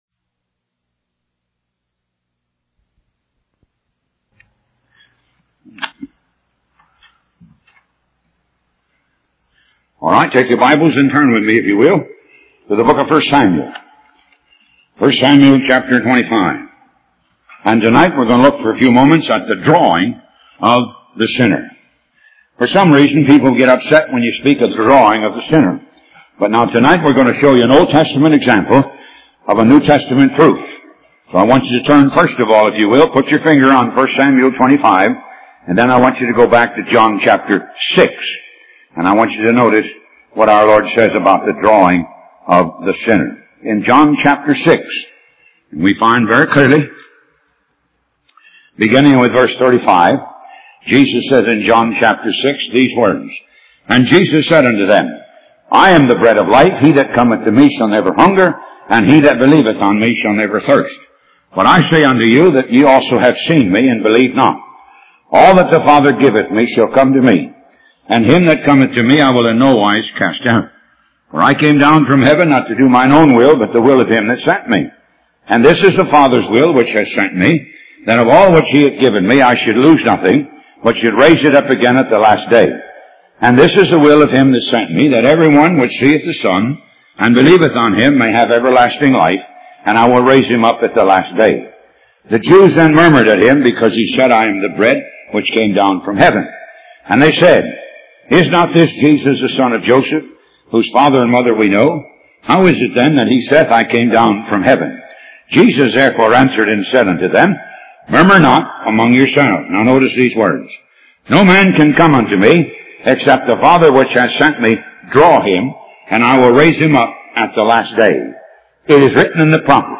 Talk Show Episode, Audio Podcast, Moga - Mercies of God Association and The Drawing of the Sinner on , show guests , about The Drawing of the Sinner, categorized as Health & Lifestyle,History,Love & Relationships,Philosophy,Psychology,Christianity,Inspirational,Motivational,Society and Culture